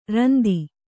♪ randi